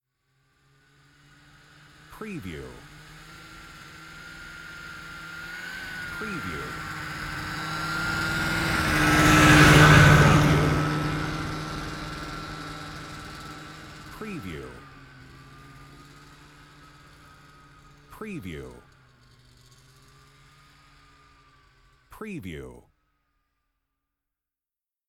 Snowmobile: pass by slow sound effect .wav #1
Description: The sound of a snowmobile passing by (slow speed)
Properties: 48.000 kHz 24-bit Stereo
Keywords: snowmobile, snow mobile, skidoo, ski-doo, ski doo, winter, passby, pass by
snowmobile-pass-by-slow-preview-01.mp3